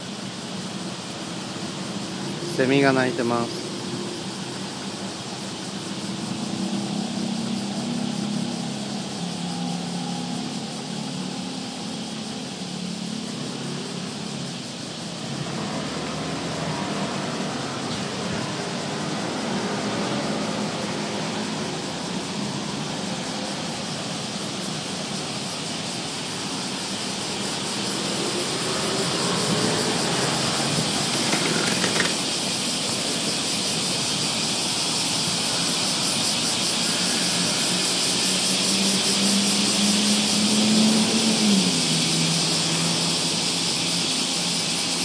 セミの声